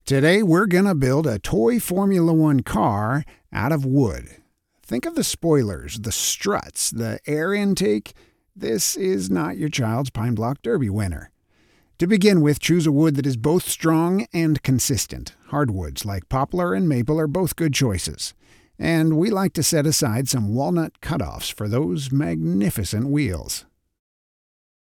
e-learning: How to build a toy car
I have the energetic voice of a man grateful for the bounty life offers, the gravelly voice of a lifelong outdoorsman, the deep voice of a man who has raised children, the generous voice of a teacher who has forgiven thousands of students, and most of all the ironic voice of a man who has forgiven himself for countless stumbles.